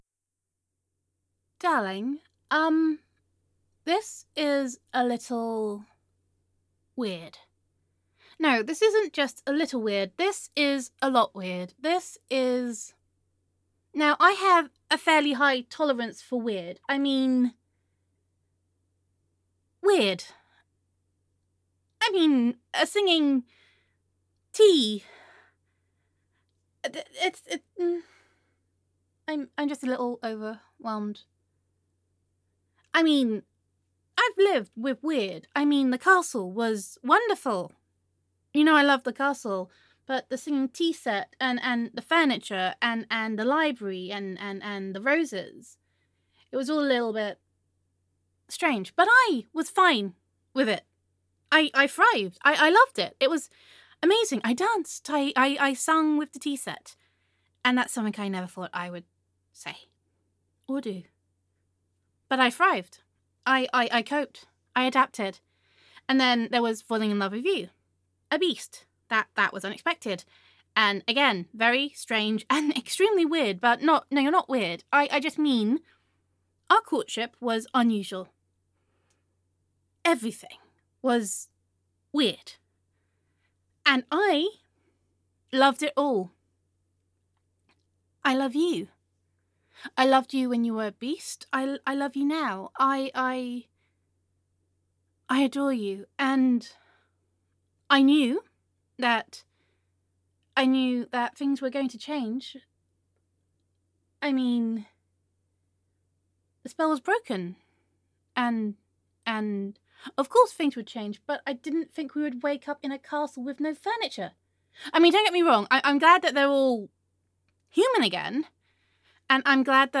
[F4A] Adorabelle